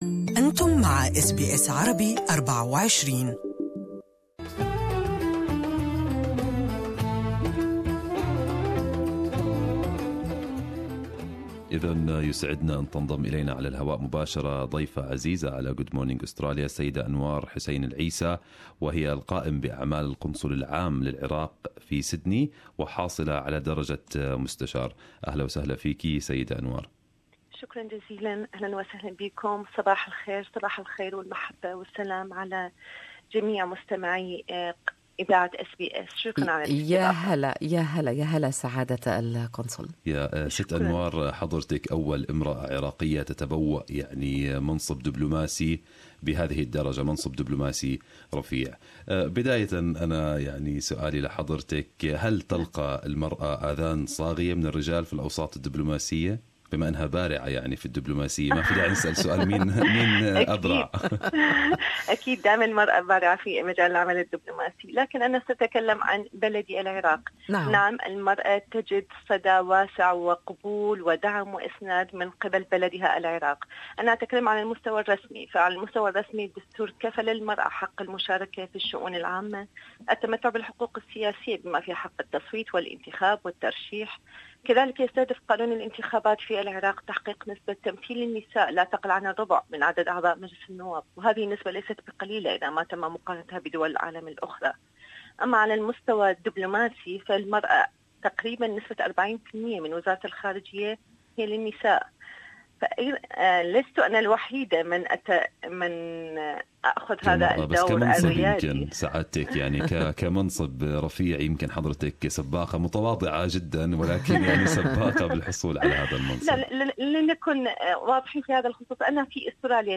Good Morning Australia interviewed her excellency, Mrs. Anwar Al-Essa to talk about the post she has recently taken as the acting Consul General at the Iraqi Consulate in Sydney.